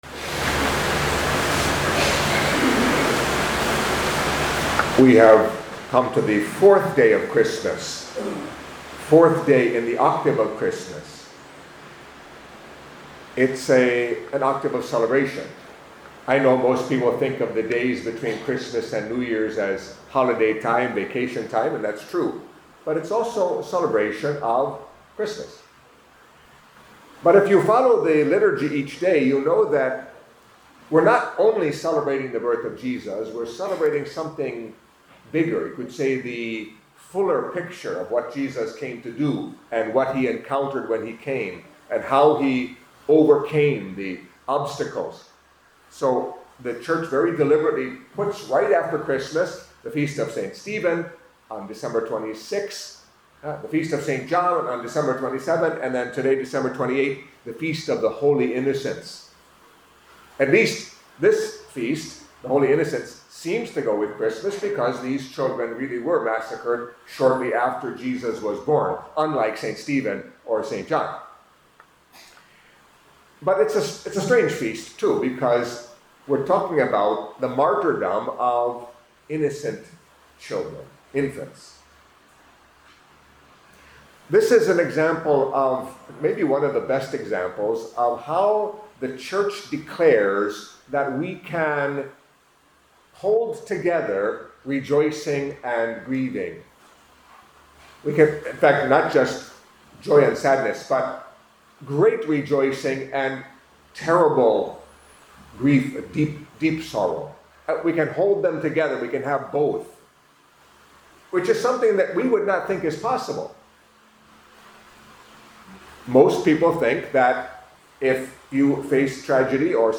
Catholic Mass homily for the Feast of the Holy Innocents